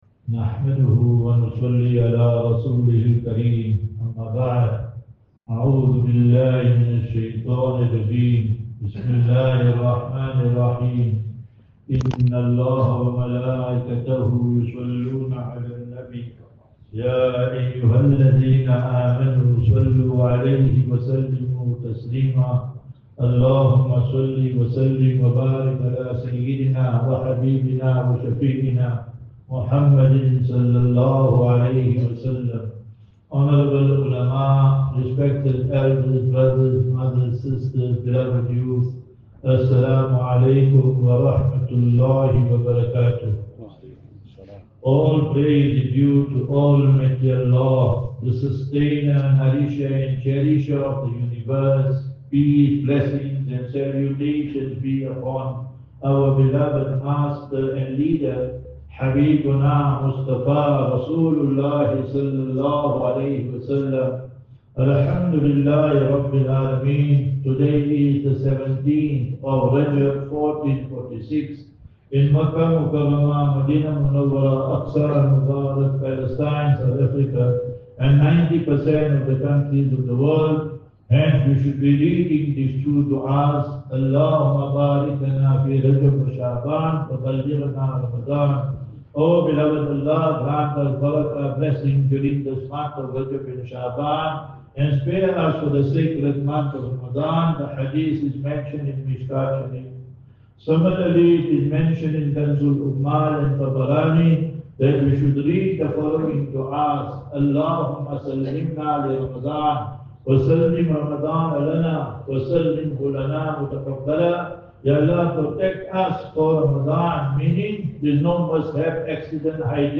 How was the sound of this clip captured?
Jumuah Lecture at Masjid Uthman - Roshnee